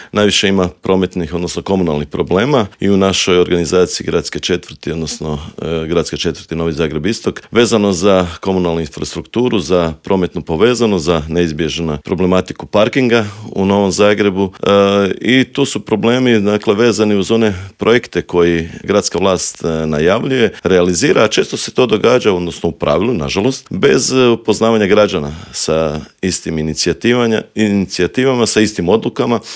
ZAGREB - U novom izdanju Intervjua Media servisa gostovao je predsjednika HDZ-a Novi Zagreb Istok i državnog tajnika u Ministarstvu poljoprivrede Tugomir Majdak s kojim smo prošli teme od gorućih problema u Novom Zagrebu, preko najavljenog prosvjeda u Sisku protiv industrijskih megafarmi i klaonice pilića pa sve do ovisnosti Hrvatske o uvozu hrane.